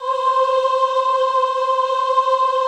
FEMALE AAH.wav